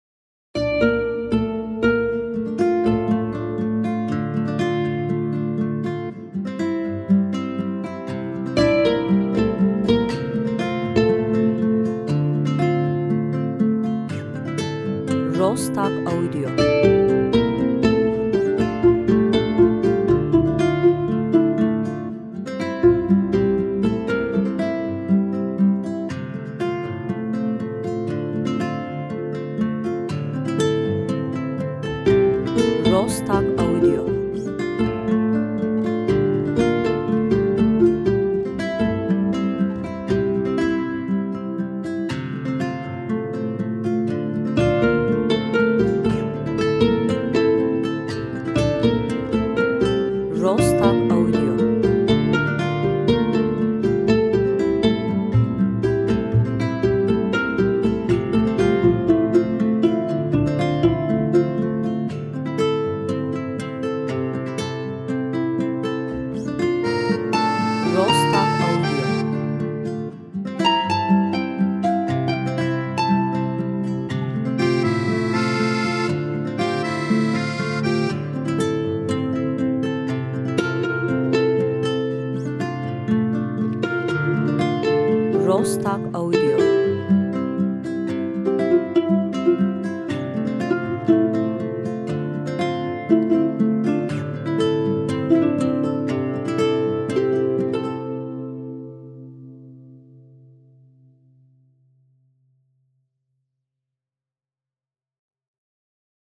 enstrümantal